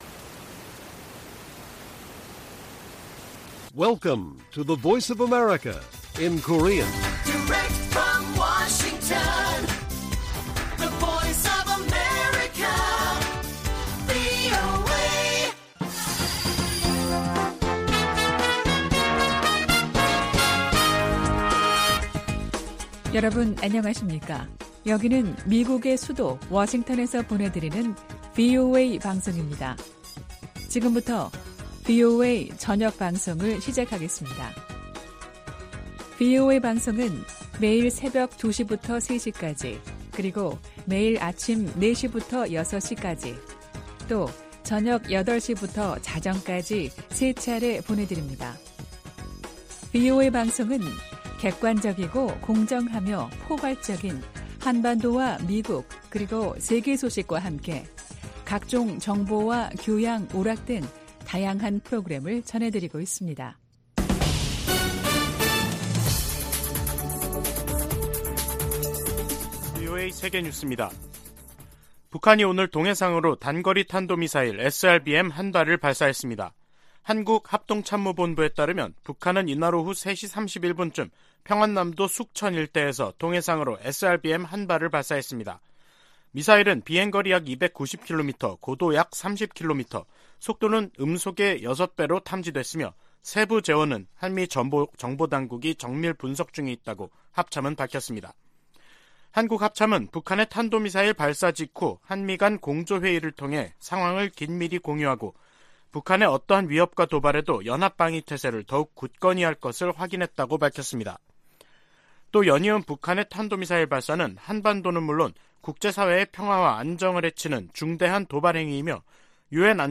VOA 한국어 간판 뉴스 프로그램 '뉴스 투데이', 2022년 11월 9일 1부 방송입니다. 북한이 9일 오후 평안남도 숙천 일대에서 동해상으로 단거리 탄도미사일 1발을 또 발사했습니다. 8일 투표를 끝낸 미국 중간선거는 개표를 진행하고 있습니다.